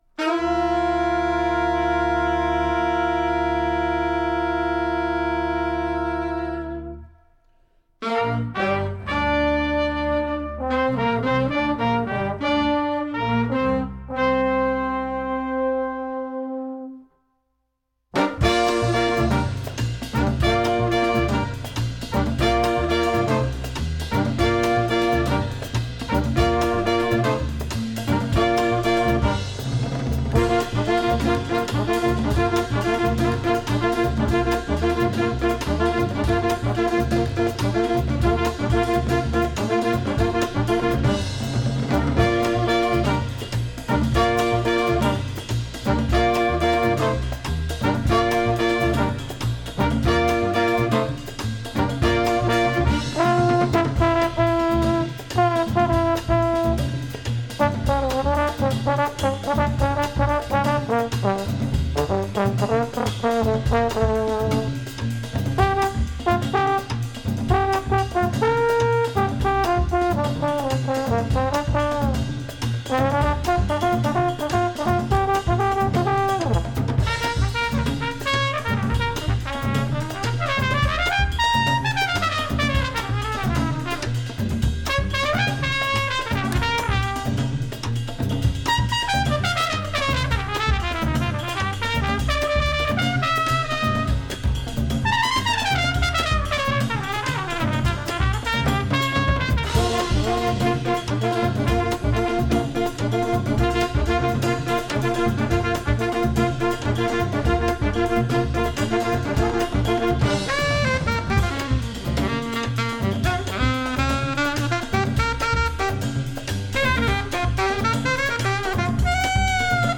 Recorded at the Maid’s Room, NYC
trombone
trumpet
alto saxophone
drums & percussion
Stereo (Pro Tools)